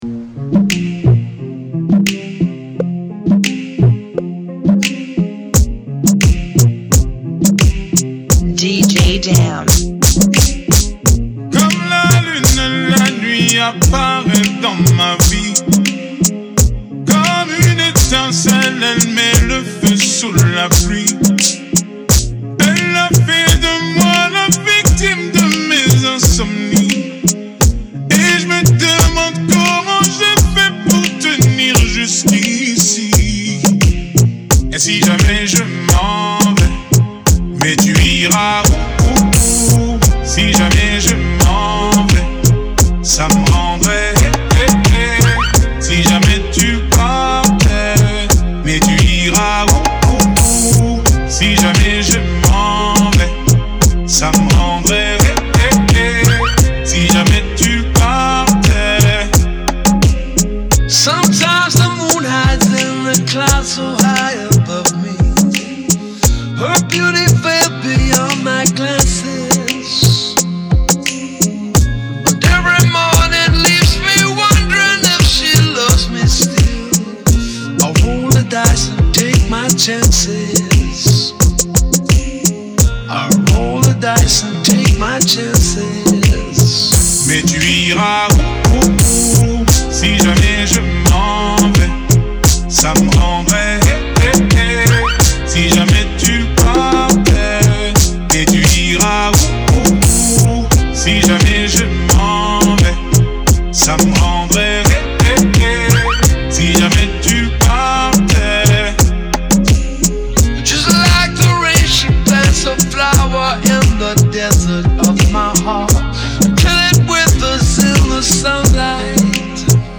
Genre: Kizomba Remix